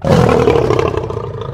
lion2.wav